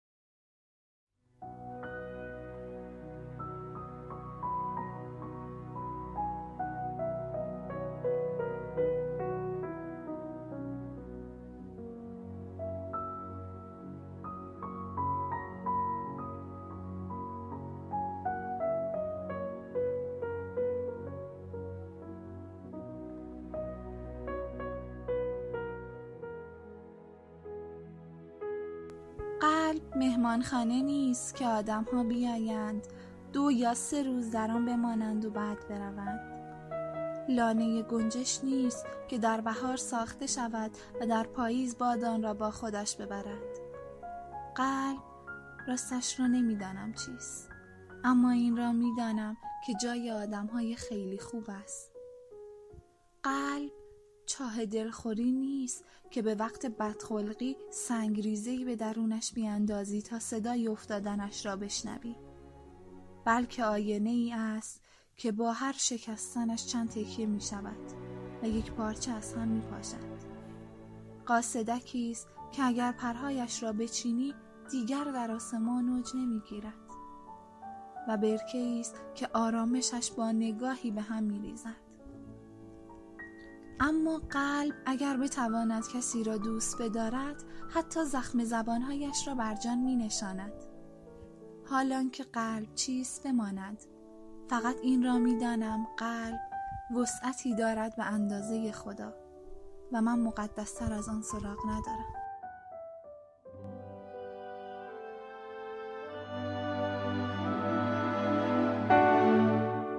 قطعه موسیقی اثر بتهوون